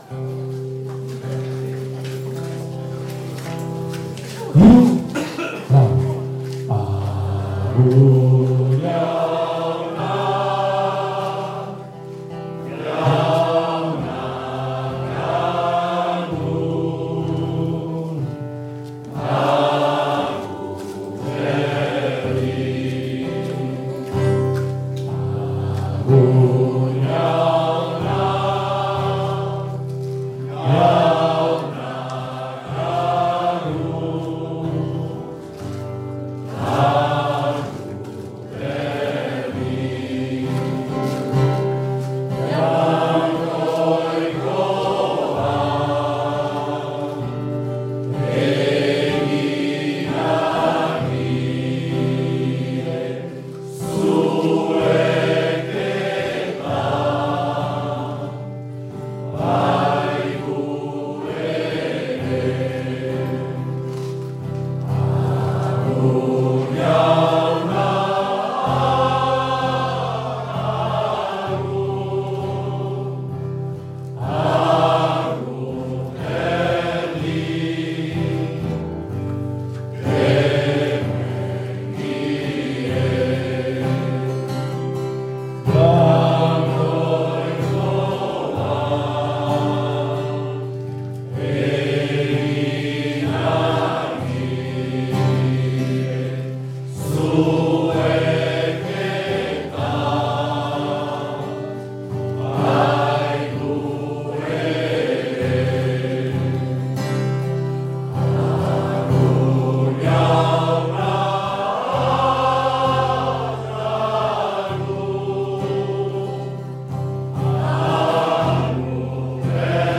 Chant
Il regroupe tous les 15 jours une cinquantaine de personnes autour d'un chant traditionnel basque.